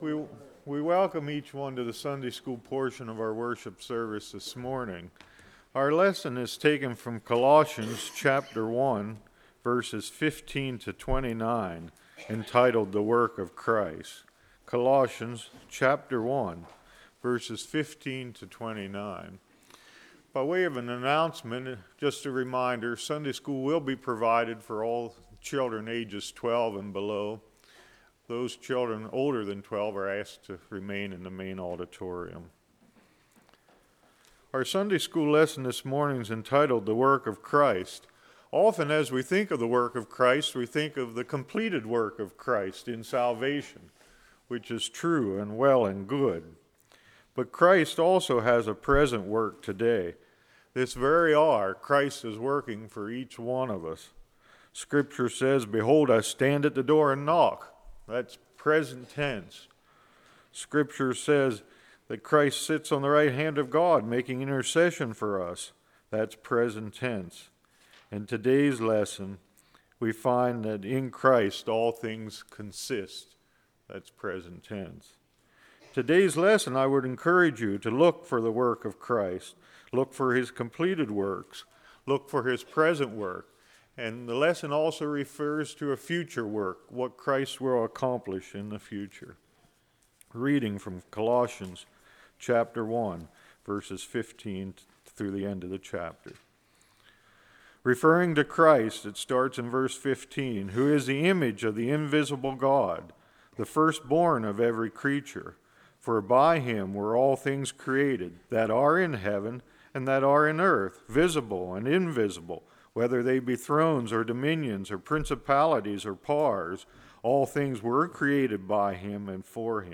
Series: Spring Revival 2014 Passage: Colossians 1:15-29 Service Type: Sunday School